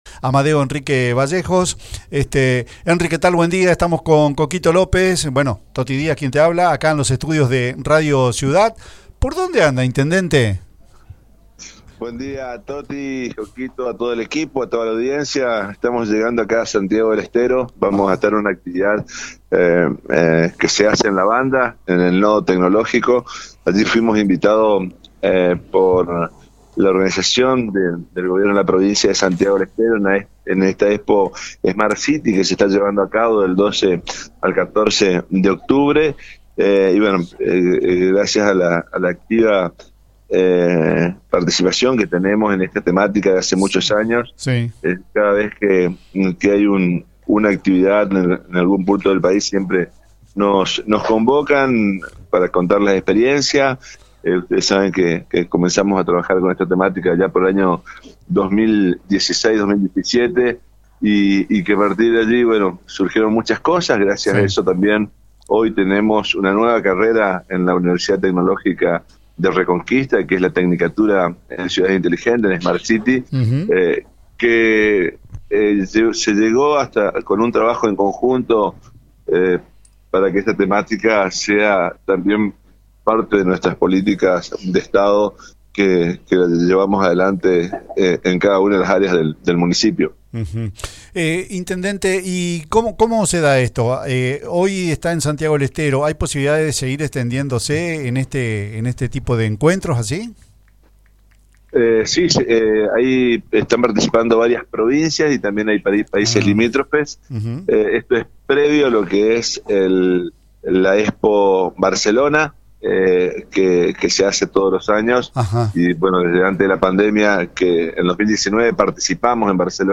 Escucha la entrevista que brindo el intendente en Radio Ciudad.